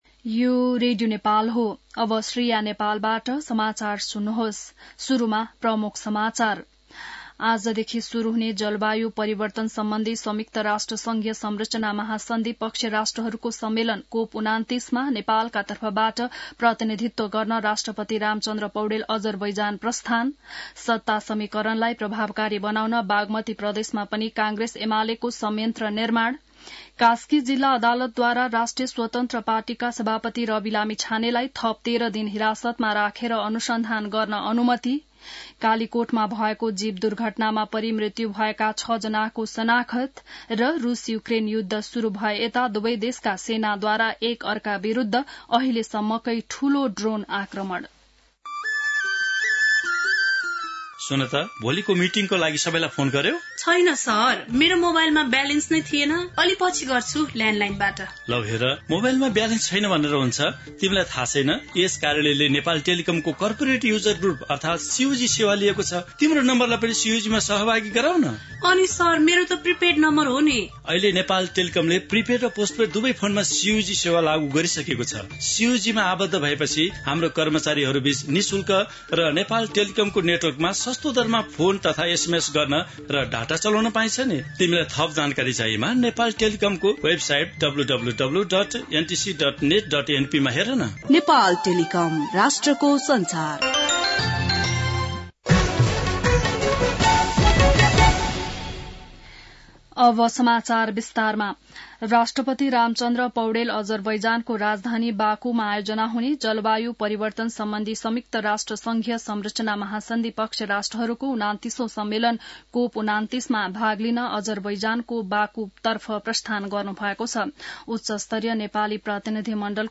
बिहान ७ बजेको नेपाली समाचार : २७ कार्तिक , २०८१